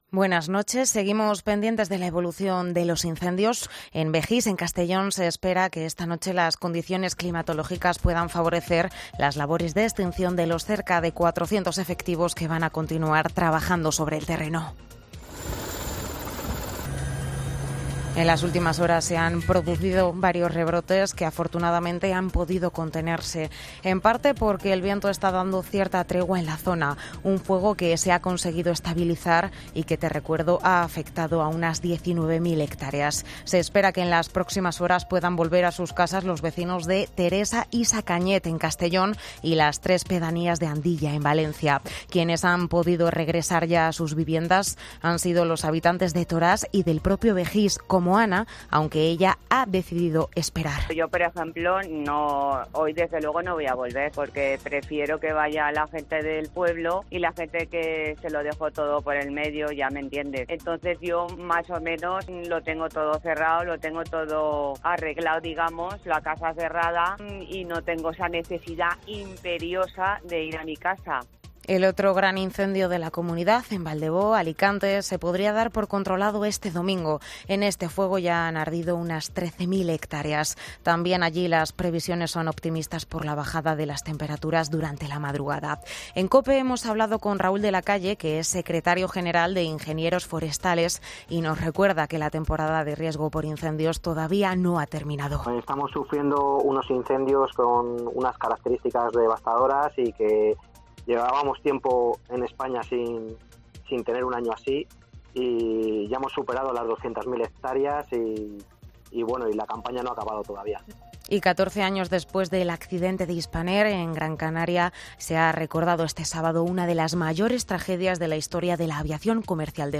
Boletín de noticias de COPE del 21 de agosto de 2022 a las 01.00 horas